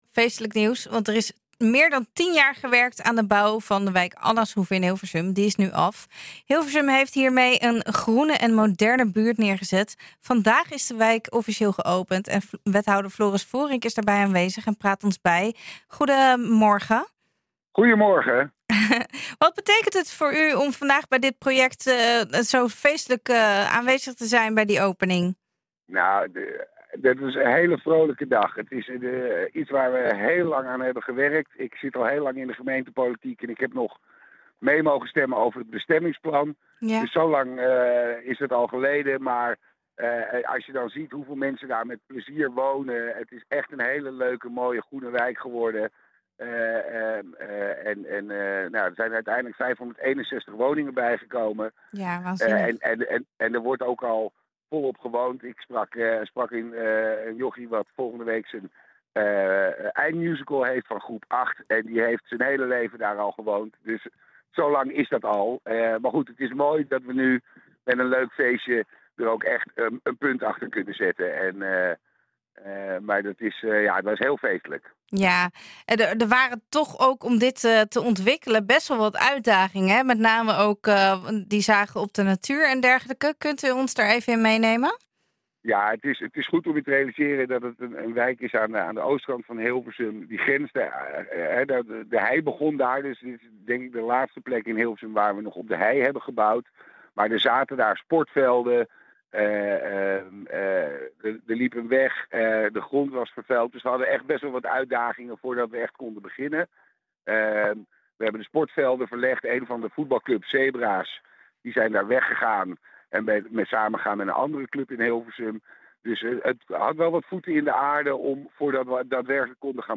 Hilversum heeft hiermee een groene en moderne buurt neergezet. Vandaag is de wijk officieel geopend. Wethouder Floris Voorink is daarbij aanwezig en praat ons bij.